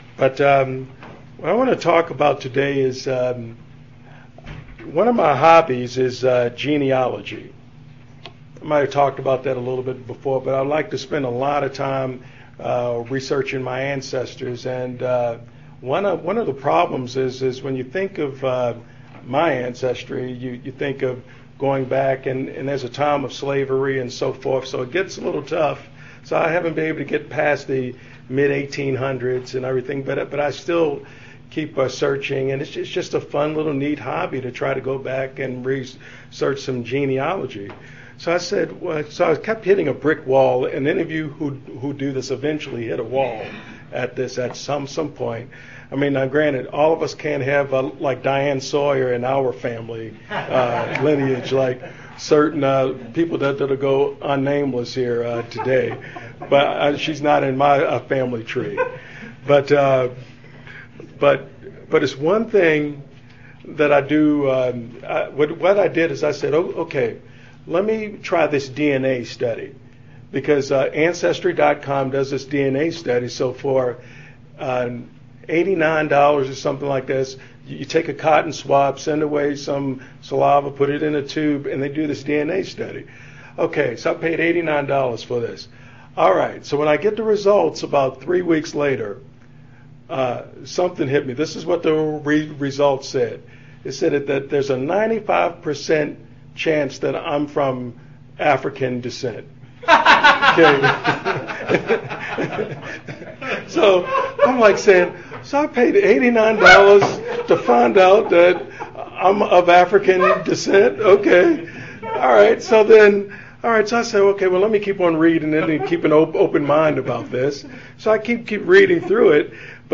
Sermons
Given in Bowling Green, KY